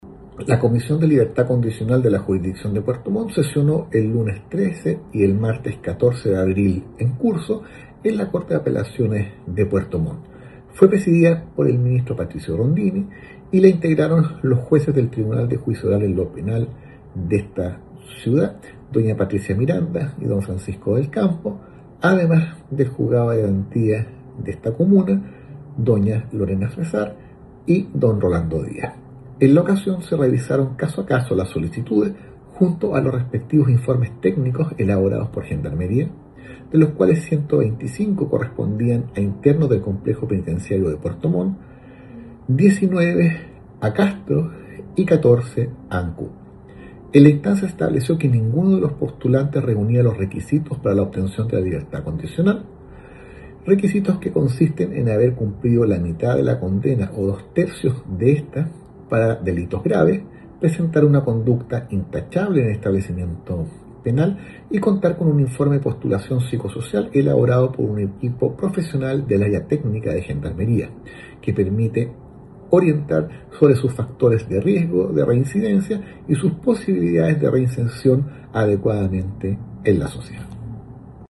Sobre la determinación de rechazar todas las peticiones, se refirió el ministro Patricio Rondini, vocero de la Corte de Apelaciones de Puerto Montt.